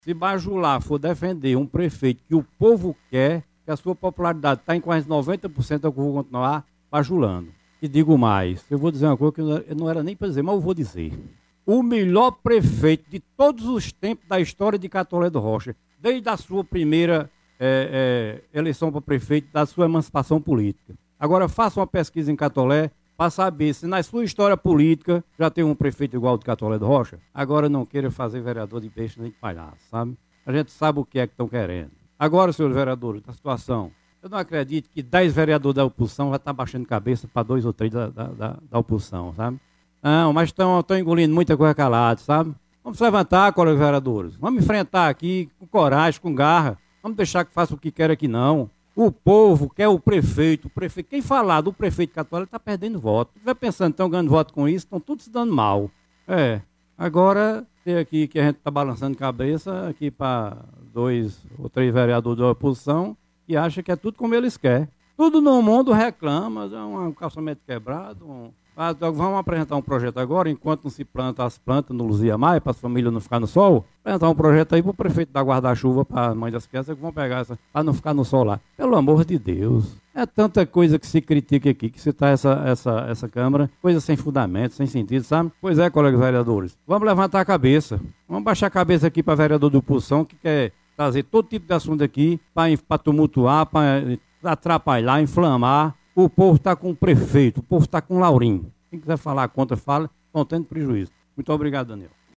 Ouça um trecho da fala do Vereador Claudio de Sinfrônio na Sessão Ordinária realizada nesta segunda – feira (20):